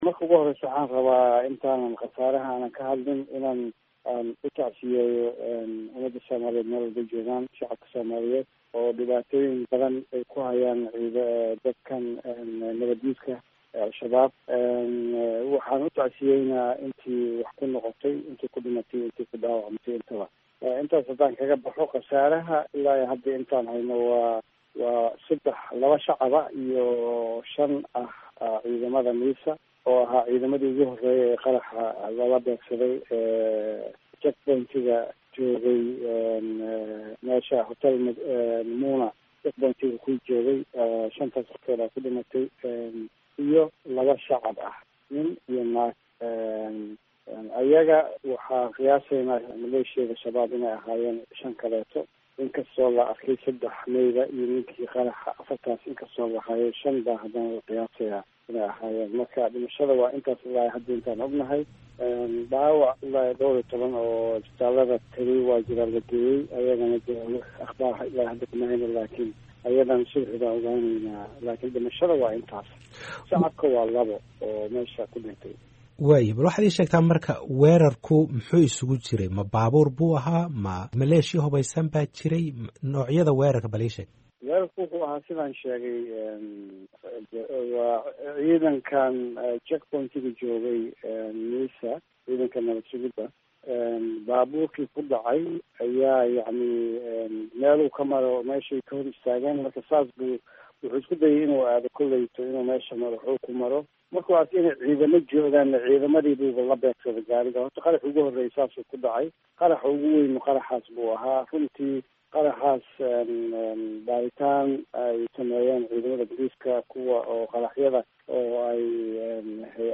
Codka-Wasiirka-Aminga-DFS.mp3